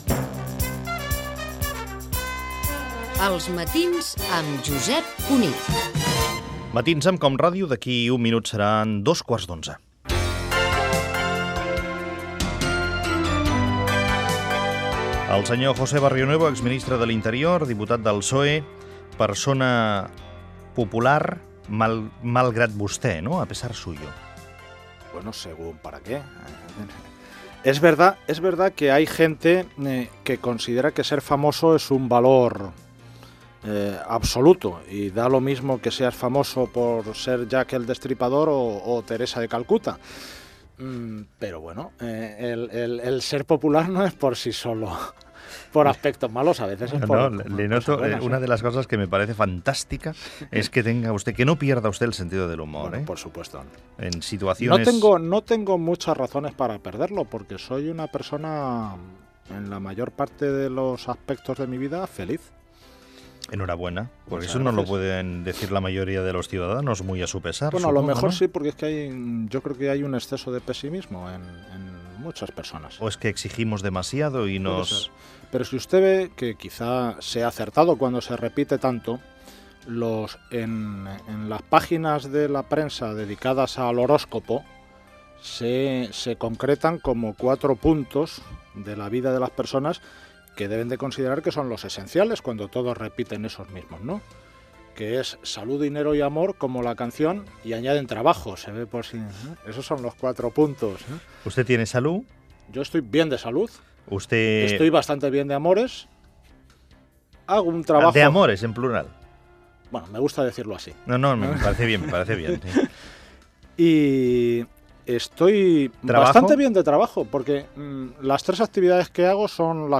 375145b74dfebb249cced2018dab172346aab024.mp3 Títol COM Ràdio Emissora COM Ràdio Barcelona Cadena COM Ràdio Titularitat Pública nacional Nom programa Els matins amb Josep Cuní Descripció Indicatiu del programa, identificació de l'esmissora i fragment de l'entrevista a l'ex ministre de l'Interior al govern socialista, José Barrionuevo.
Info-entreteniment